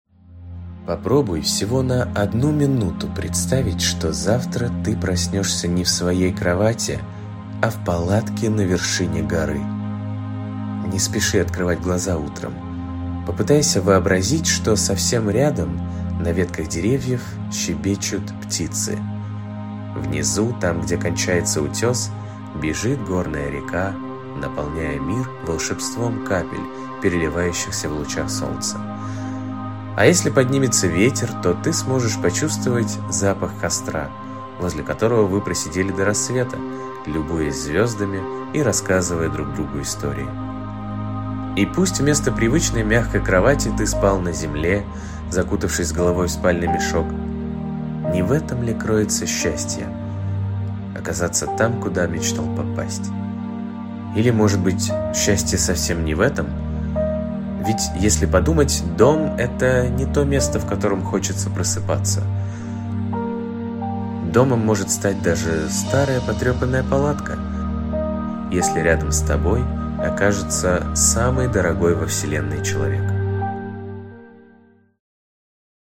Аудиокнига Я загадаю тебя на лето | Библиотека аудиокниг